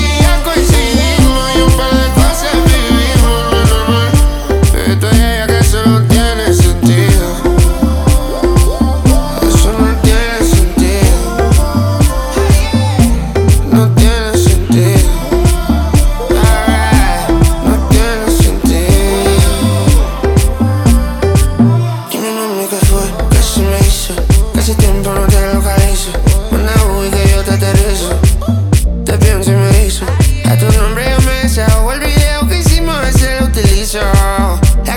Urbano latino Latin African Afro-Pop
Жанр: Поп музыка / Латино